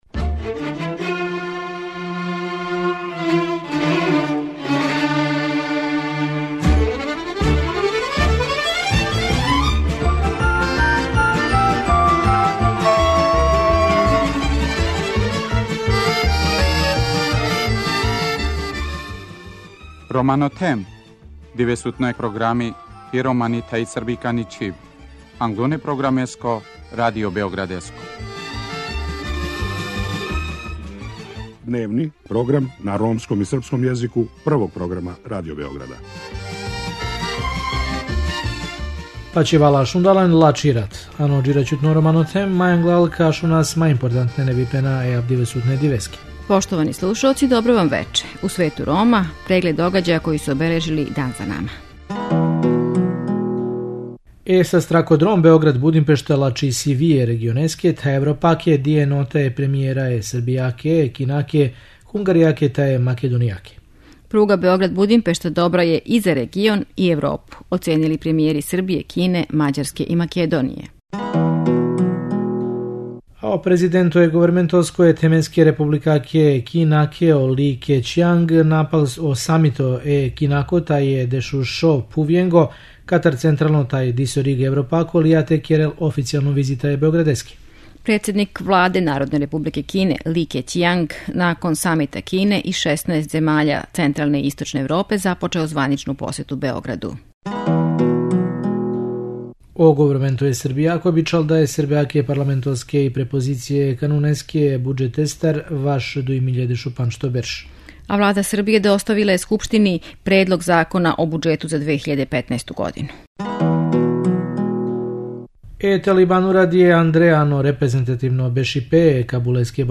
Вечерас доносимо и репортажу о тражиоцима азила који су из Немачке принудно враћени у Србију и Македонију.